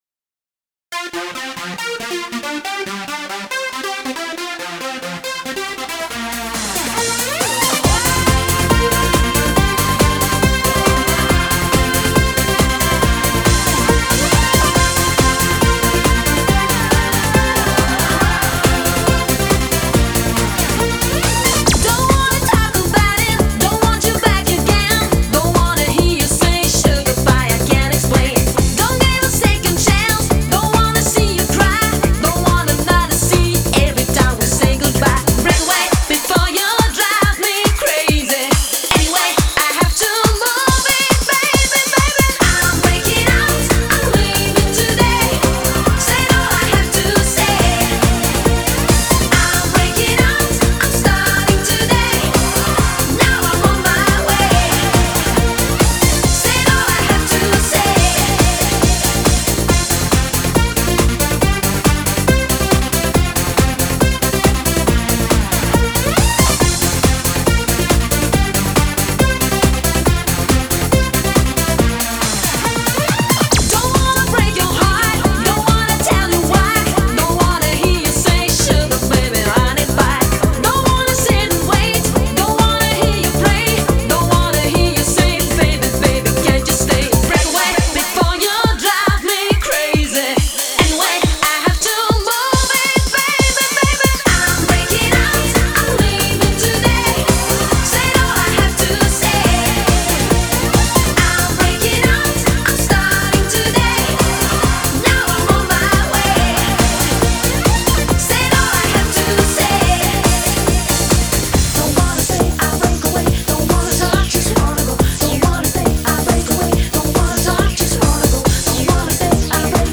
Eurodance music